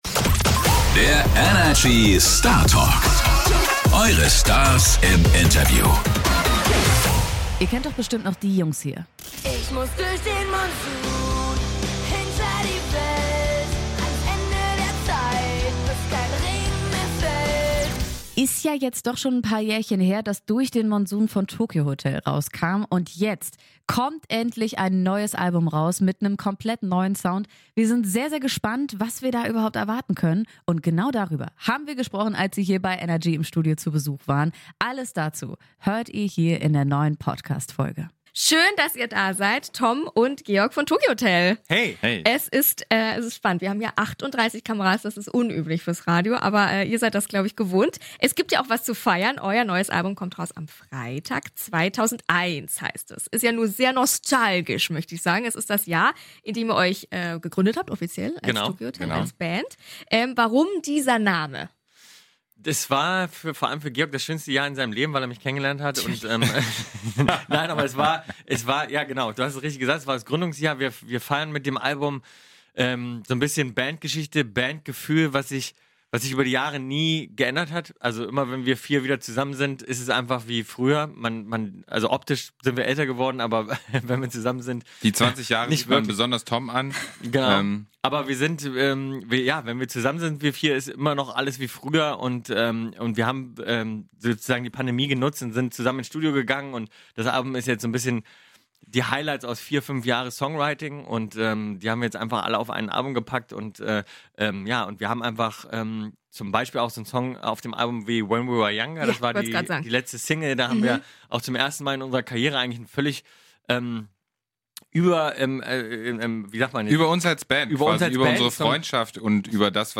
Die Jungs von Tokio Hotel haben gerade frisch ihr neues Album "2001" veröffentlicht und schauen zurück auf die Vergangenheit und setzen gleichzeitig alles auf Neustart. Wir haben mit ihnen über die neuen Songs und die Neuauflage von "Durch den Monsun" gesprochen.